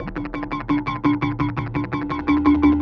Index of /musicradar/rhythmic-inspiration-samples/85bpm
RI_DelayStack_85-12.wav